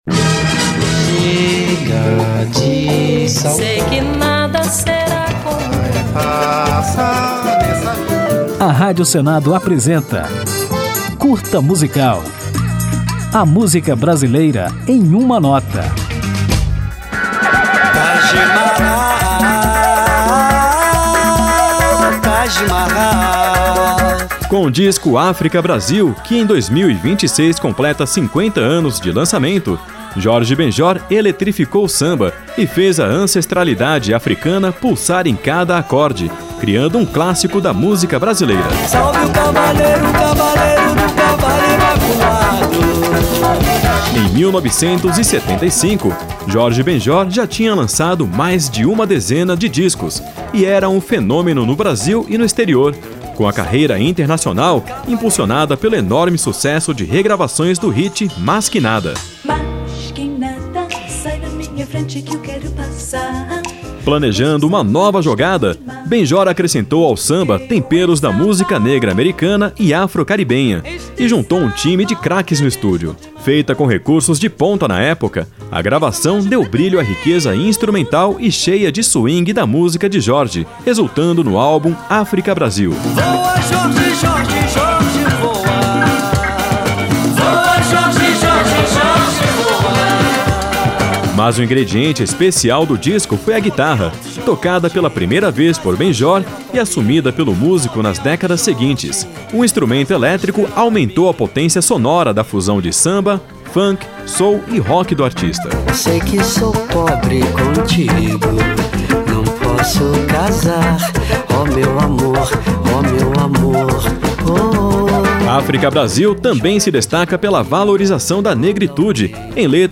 Com letras que celebram as raízes negras do povo brasileiro e uma mistura de samba com rock, soul e funk, o álbum se tornou um clássico da nossa música. Ao final, vamos ouvir Umbabarauma, música que abre o álbum África Brasil, lançado em 1976.